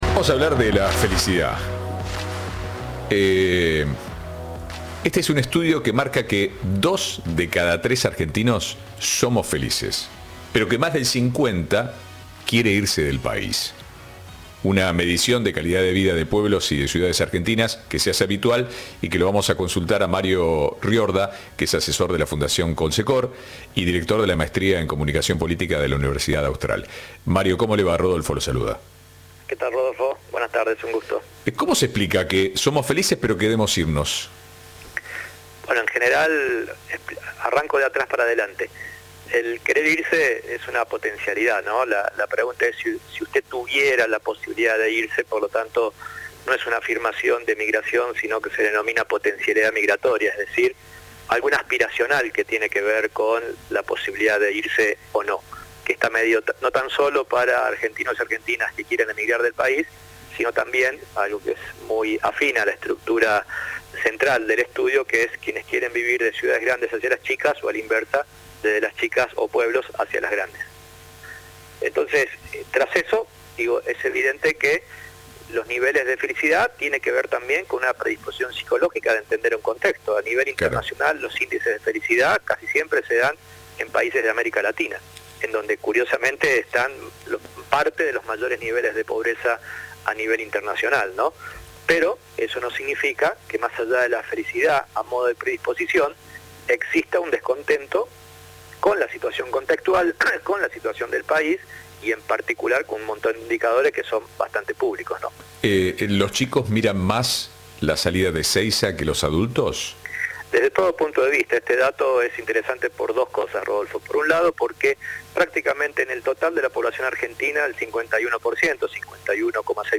Entrevista de Rodolfo Barili.